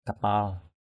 /ka-pa:l/